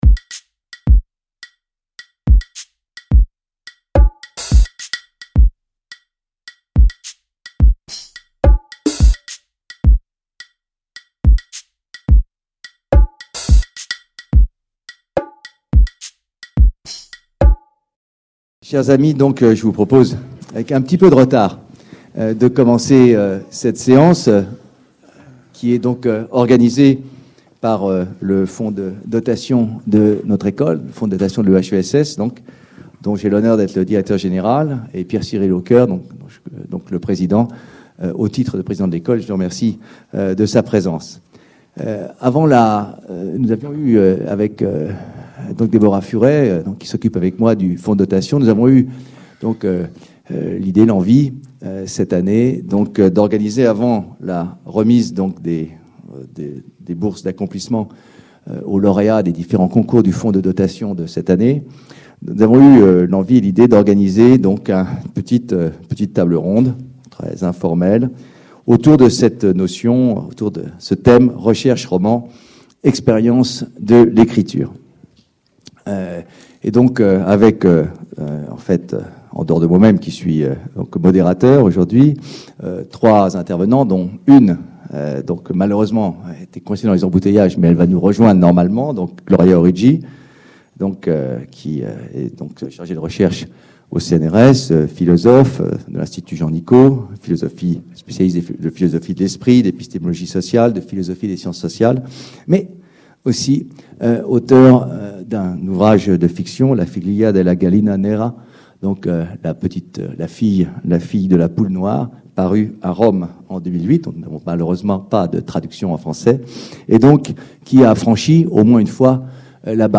À l’occasion de la remise de ses bourses d’accomplissement, le Fonds de dotation de l'EHESS et les Amis de la mention études politiques (AMEP) organisent une table ronde autour de la question de la porosité entre l'écriture de recherche et l'écriture de fiction.